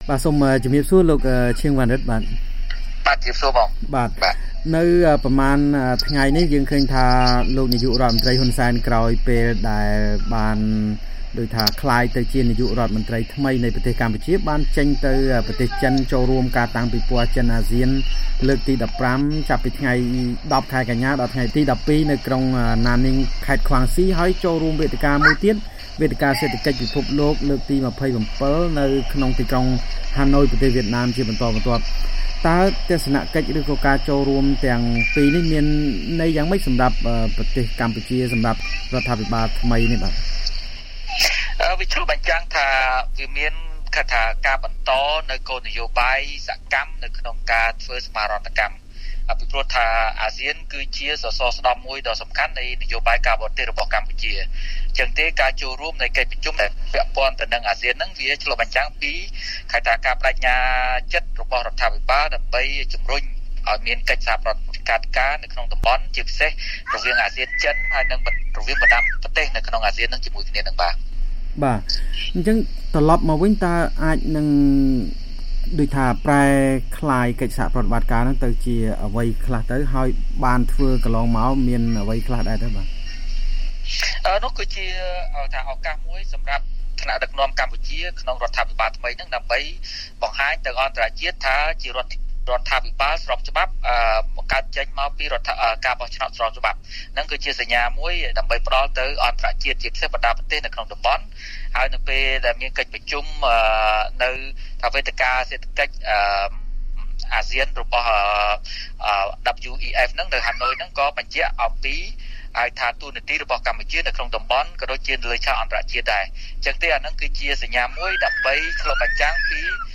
បទសម្ភាសន៍ VOA៖ អ្នកវិភាគពន្យល់ពីទស្សនកិច្ចលោកហ៊ុន សែនក្នុងប្រទេសចិននិងវៀតណាម